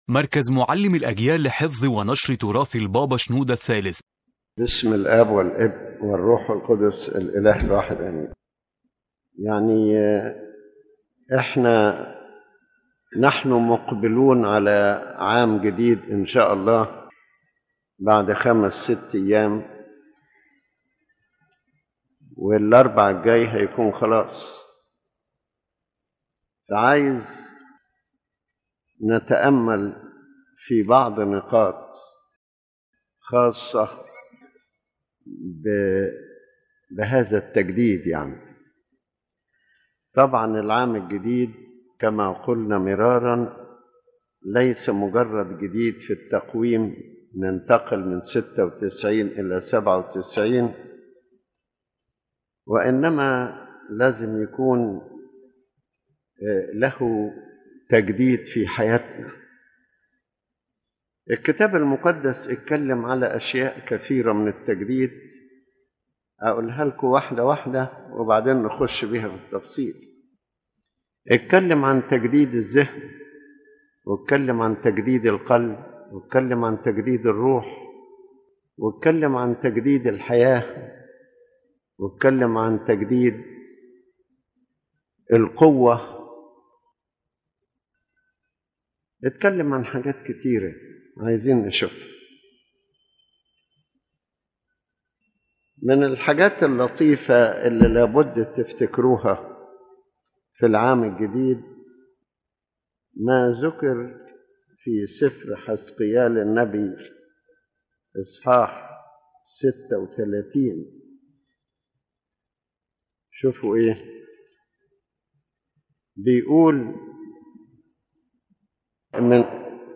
His Holiness Pope Shenouda III speaks about the meaning of entering a new year from a spiritual perspective, emphasizing that true renewal is not a change of calendar or time, but a transformation of the human being from within—heart, mind, spirit, and conduct.